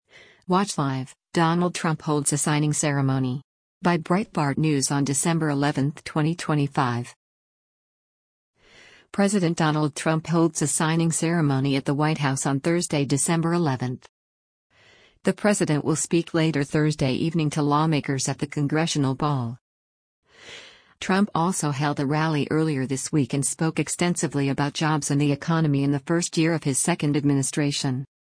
President Donald Trump holds a signing ceremony at the White House on Thursday, December 11.